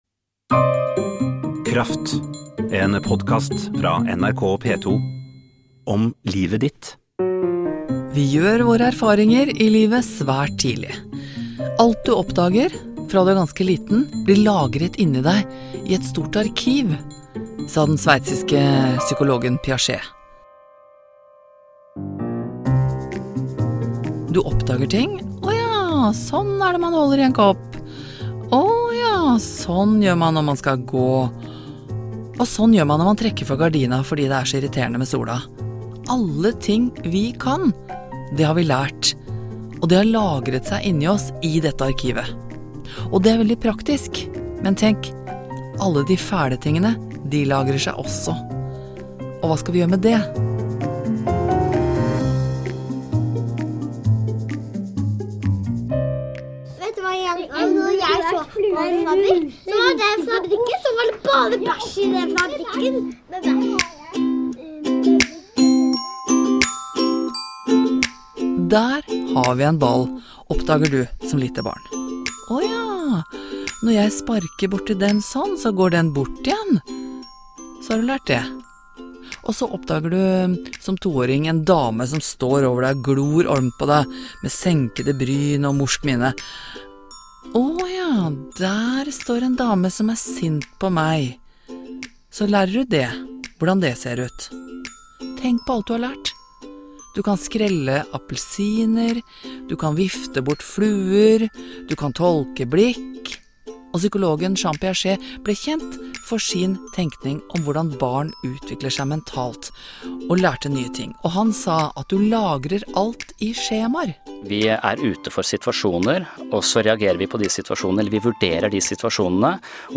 Det var en hyggelig og interessant samtale som endte opp som en episode i hennes Podcast.